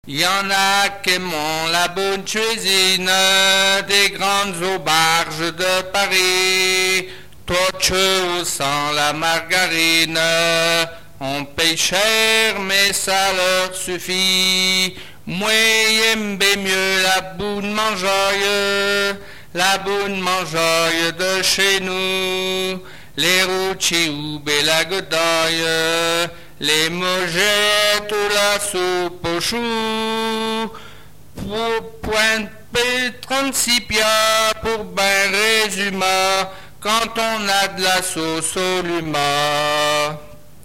Lettrées patoisantes
Pièce musicale inédite